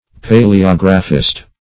Paleographist \Pa`le*og"ra*phist\ (p[=a]`l[-e]*[o^]g"r[.a]*f[i^]st), n.